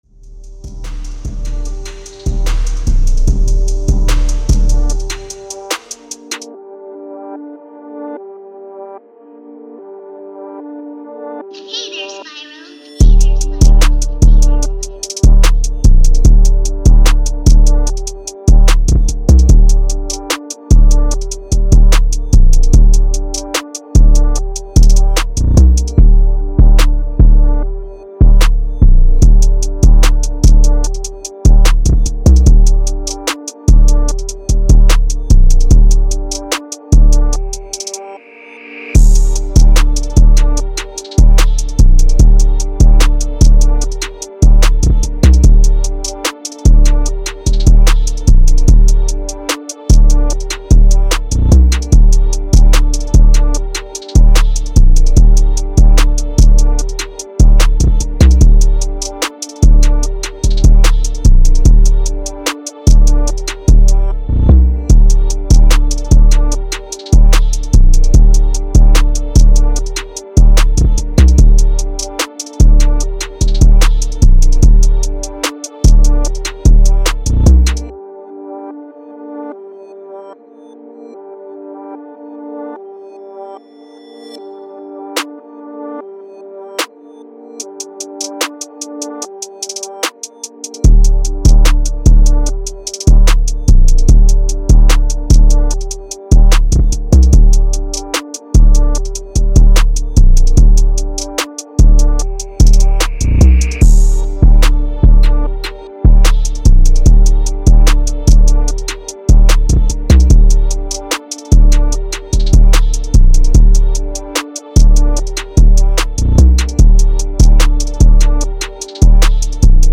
Melodie hlavně piano nebo trap melodie
projekt 287 southside trapik 140 BPM F#m.mp3